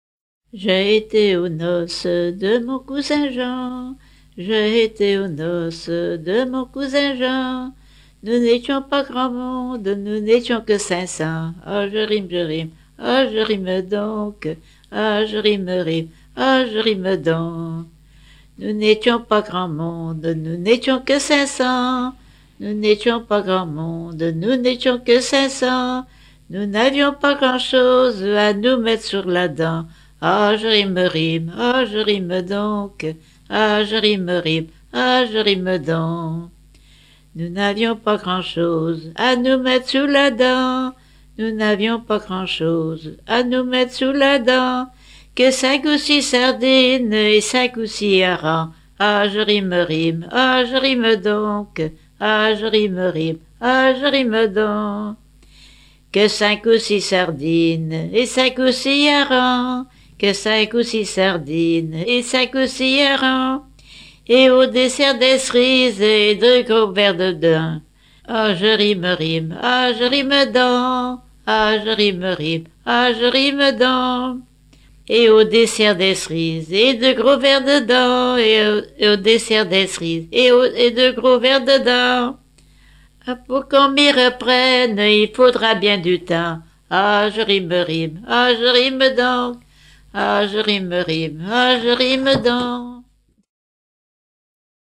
Dompierre-sur-Yon
Genre laisse